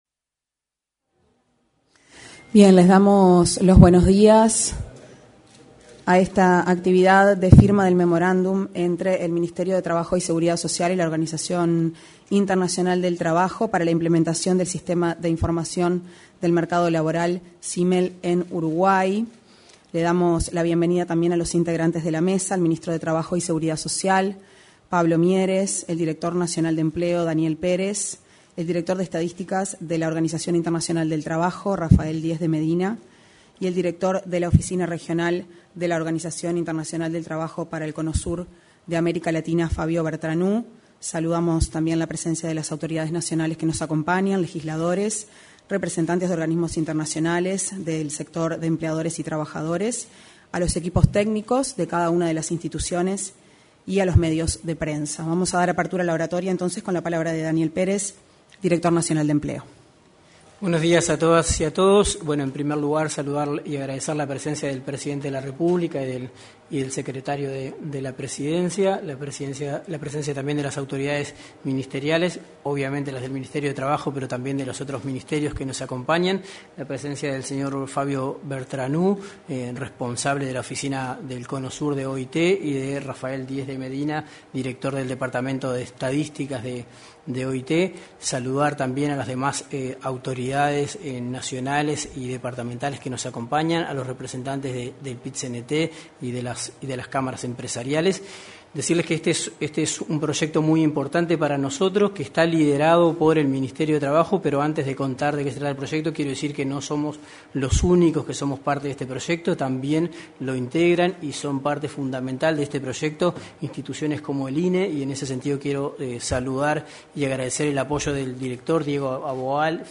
En la oportunidad, se expresaron el director nacional de Empleo, Daniel Pérez; el director de la Oficina de Estadísticas de Organización Internacional